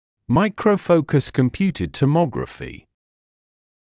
[ˌmaɪkrəu’fəukəs kəm’pjuːtɪd təˈmɒgrəfi][ˌмайкроу’фоукэс кэм’пйу:тид тэˈмогрэфи]микрофокусная компьютерная томография